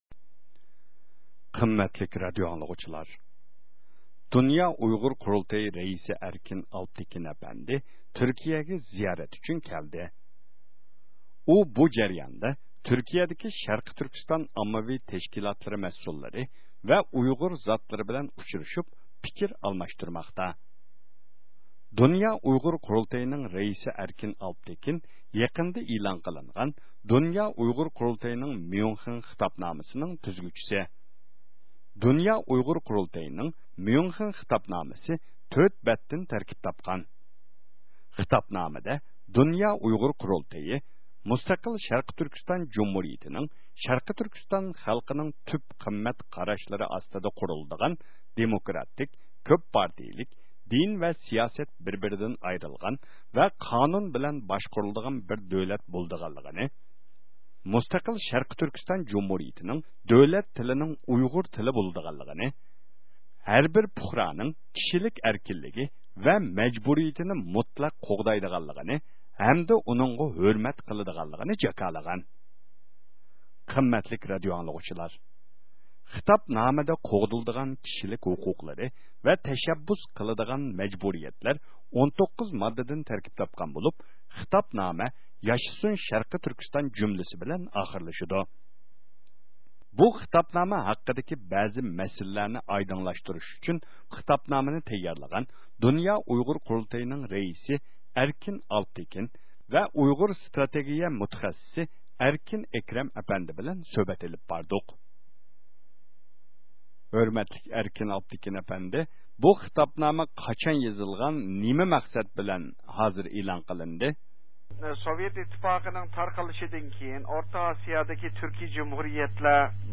سۆھبەت ئۆتكۈزدى.